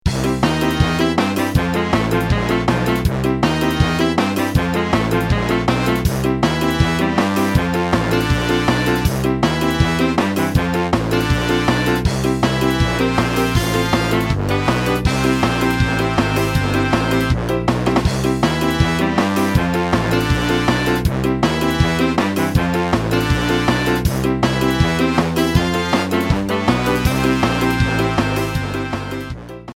Fade-out added